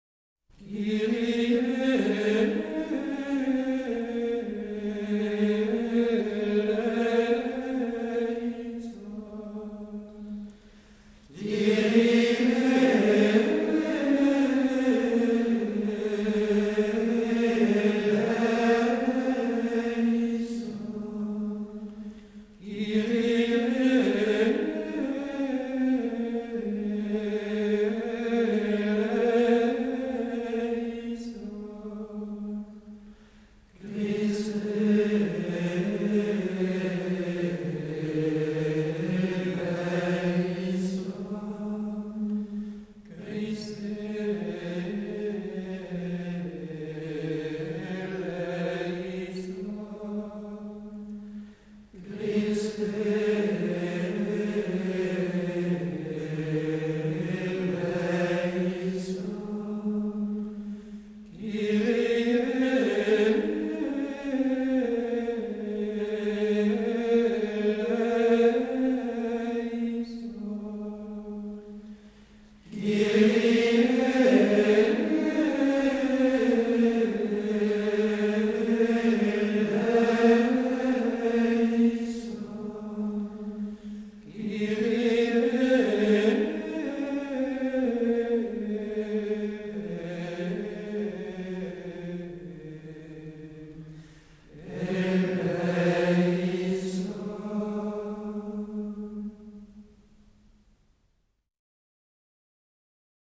Voici un Kyrie extrêmement simple et plein de beauté et de profondeur.
Le 8e mode donne de la profondeur à cette pièce qui semble à première vue très légère.
En réalité, ce beau chant gagne en expression quand il est revêtu de calme, de legato.
Ce Kyrie réalise donc deux courbes, une davantage prononcée, l’autre plus modeste, mais le tout dans une atmosphère très paisible, sans accident.
Il convient de chanter ces Christe avec beaucoup plus de retenue, dans un tempo large, et avec des voix plus piano.
Kyrie-12-Solesmes.mp3